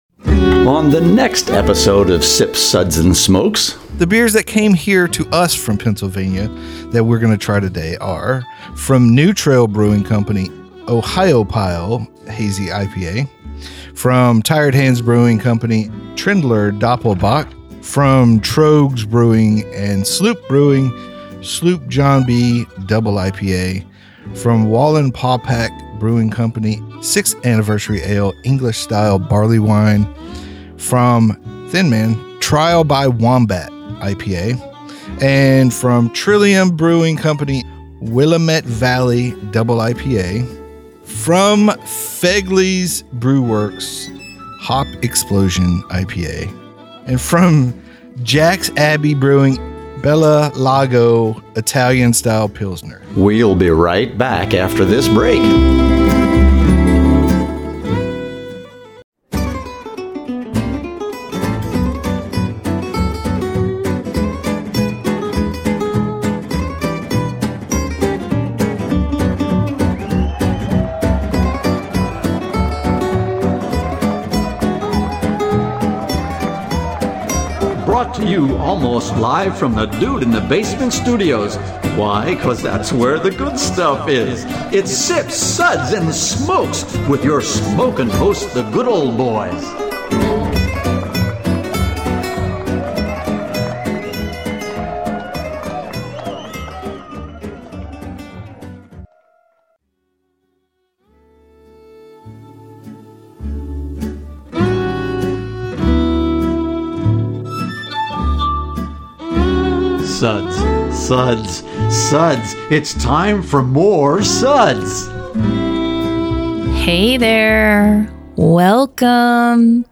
Radio MP3 with Music Beds
Location Recorded: Nashville,TN